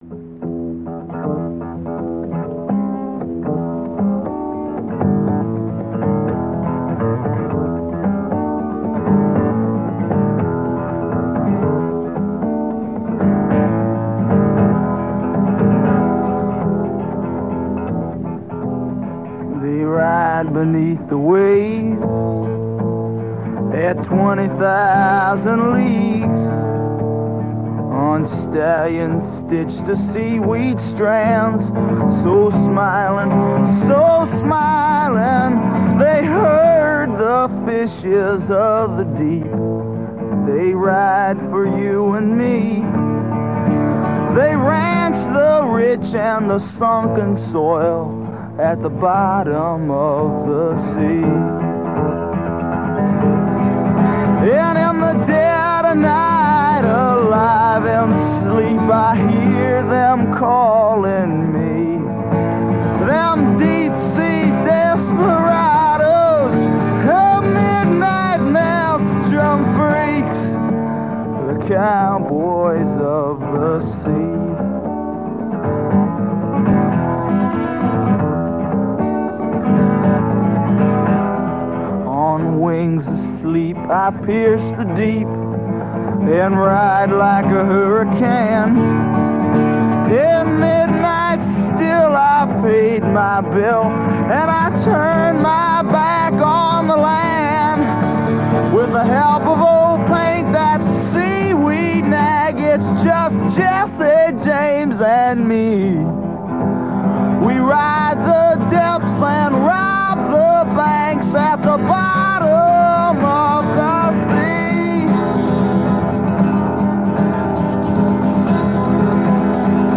powerful, psychedelic full-band arrangement
blisteringly, jaw-droppingly fast mid-song guitar solo